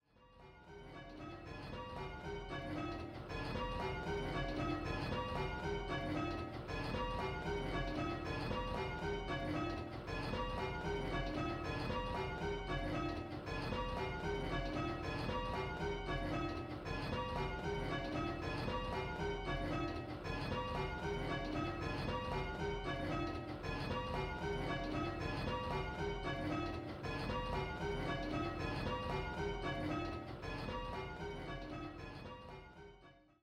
5 & 6 Bell Game